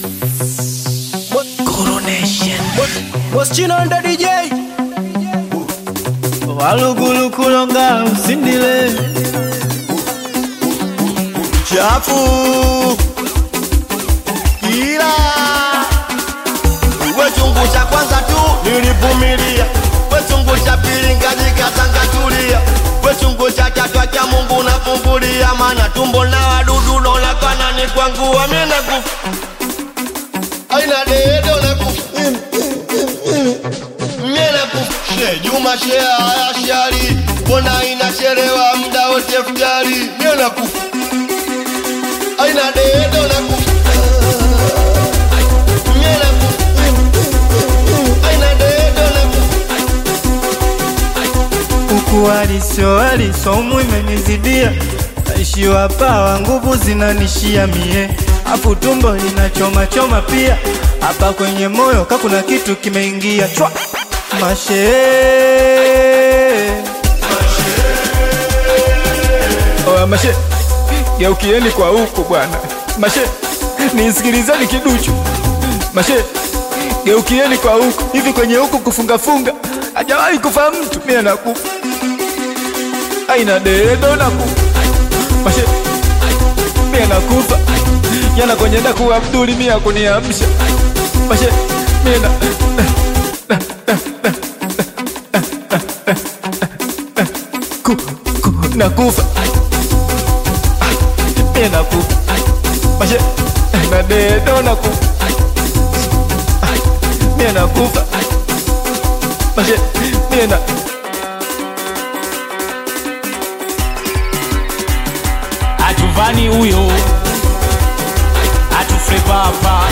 Singeli
Bongo Flava/Hip-Hop collaboration